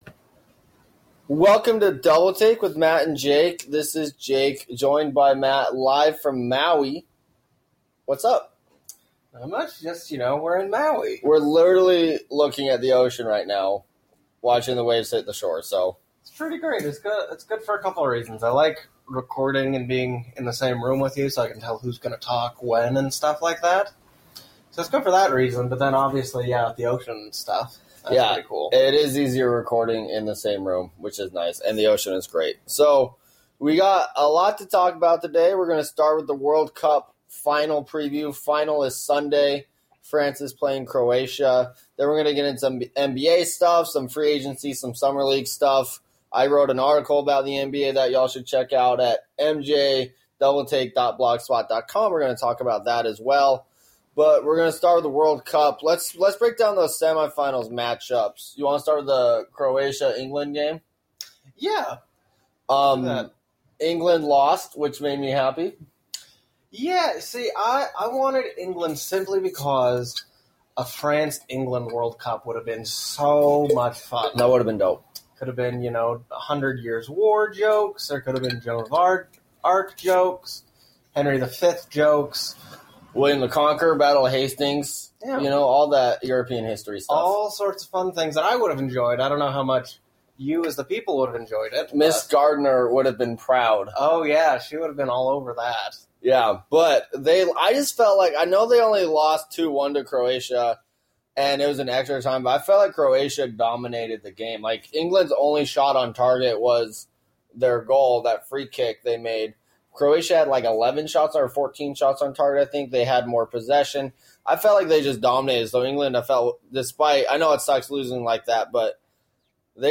Live from Maui